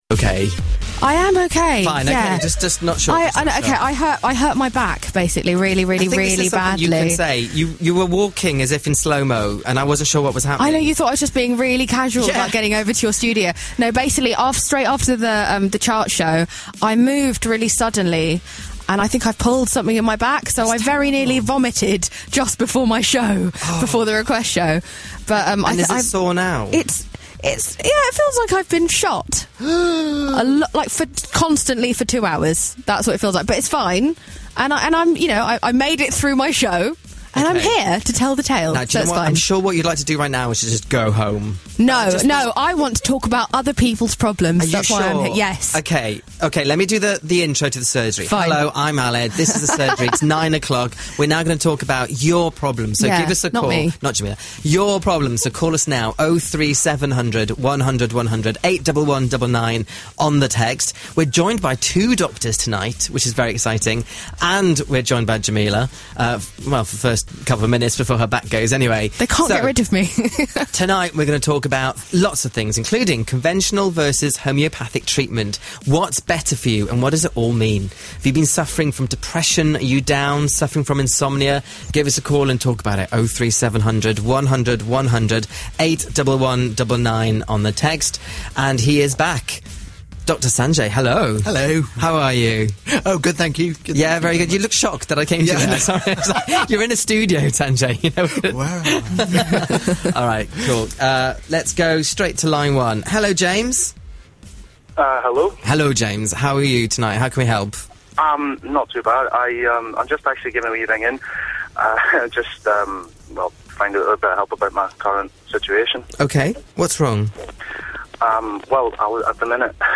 Music removed.